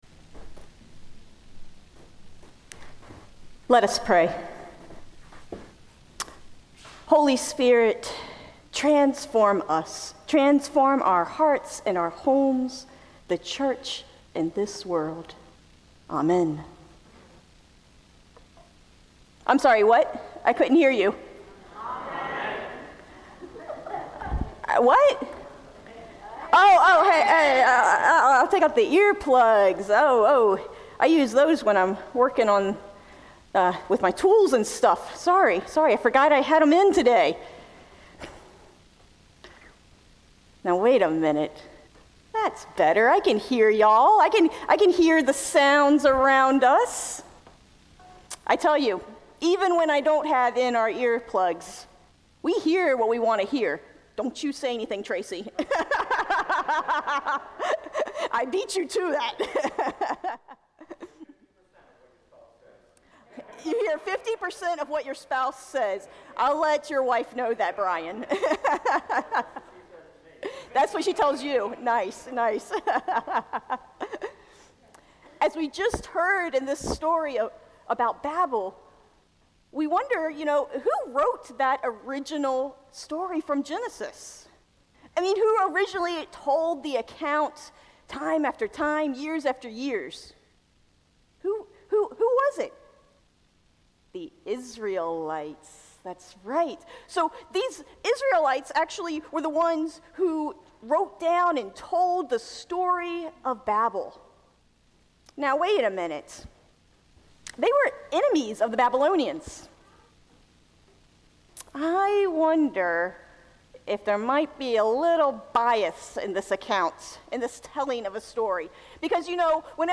Pentecost sermon